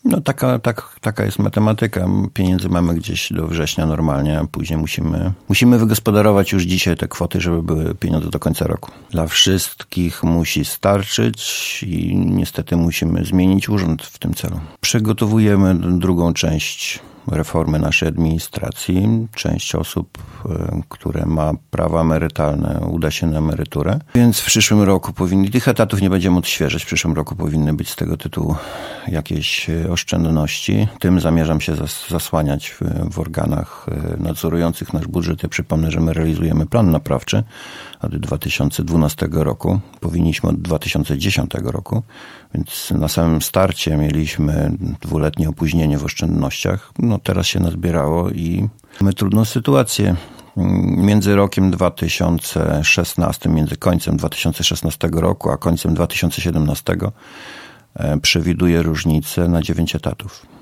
Szczegóły burmistrz przedstawił we wtorek (21.03) w Radiu 5.